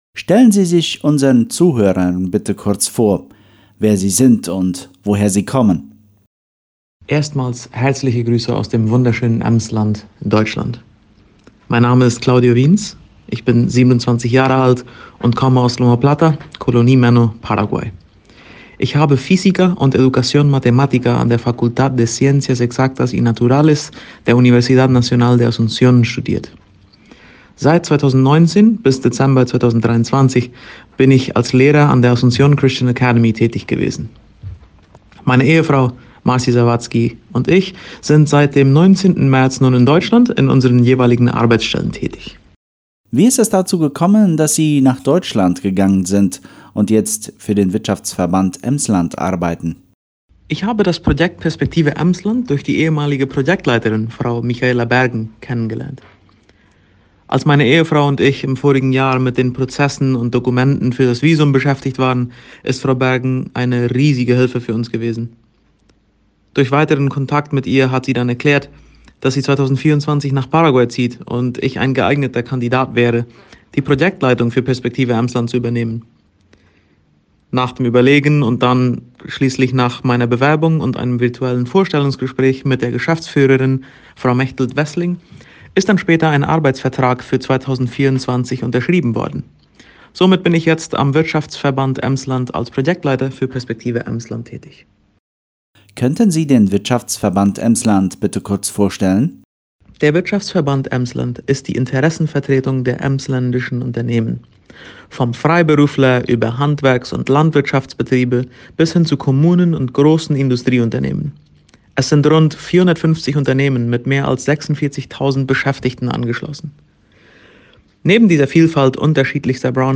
Interview Perspektive Emsland